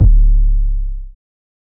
Wave 808 - C#.wav